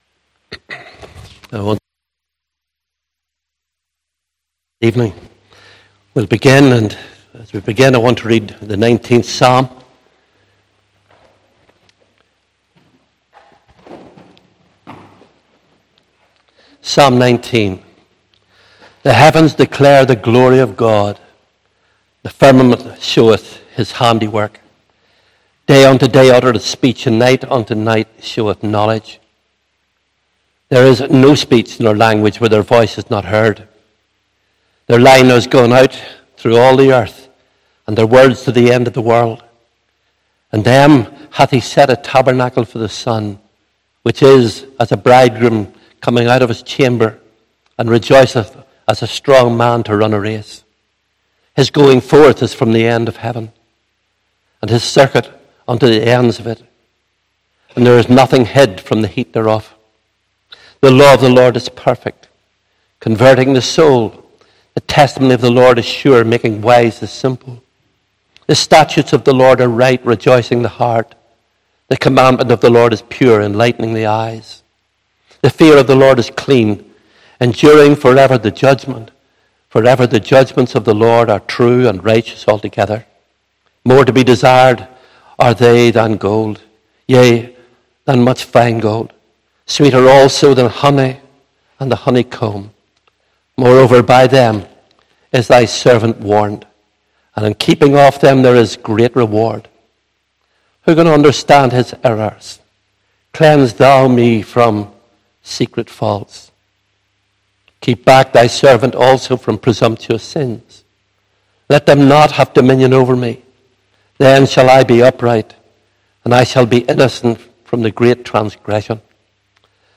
32 Service Type: Evening Service Bible Text